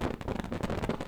Fire Shake.wav